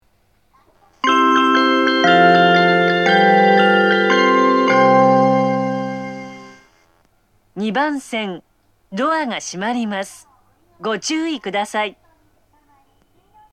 発車メロディー
一度扱えばフルコーラス鳴ります。
放送更新された際、低音ノイズが被るようになっています。